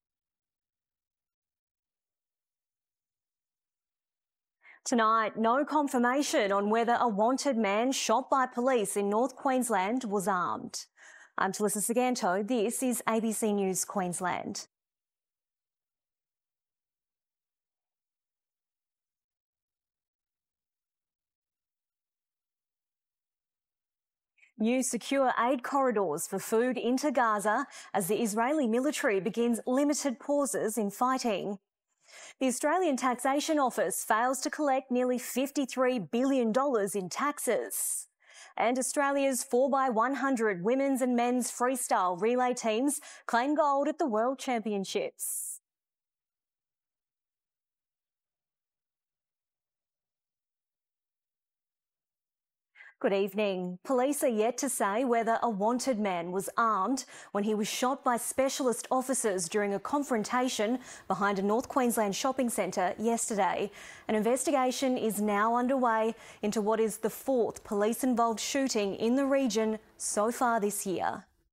reading the headlines.